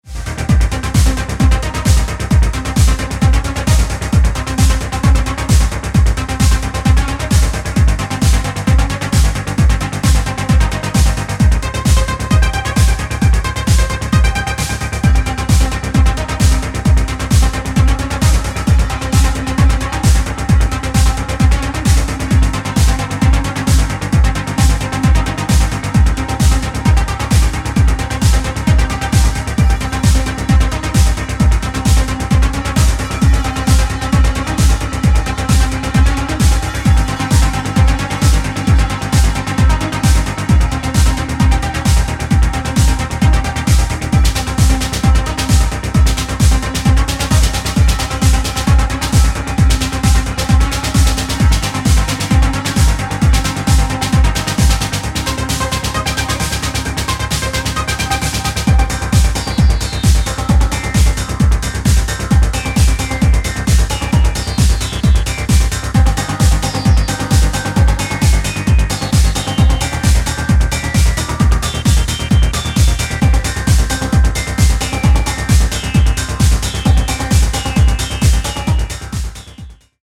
Exploring a dark fractal sound!